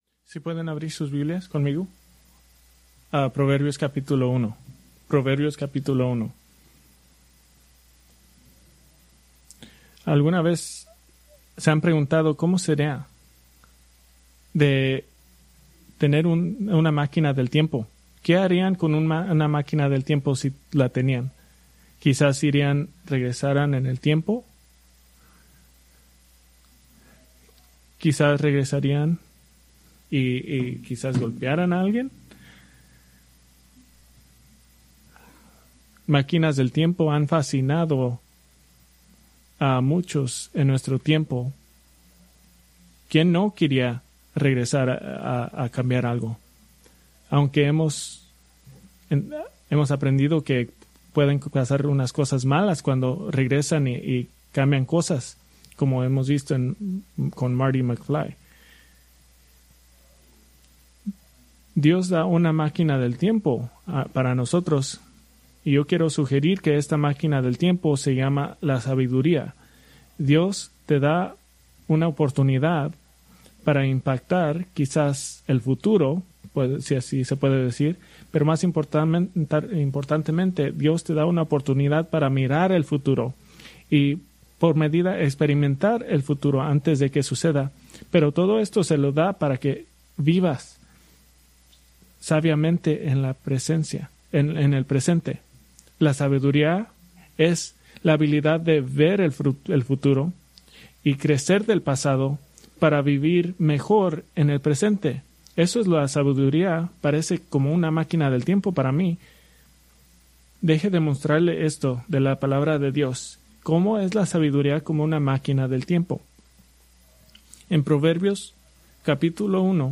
Preached July 20, 2025 from Proverbios 1:8-33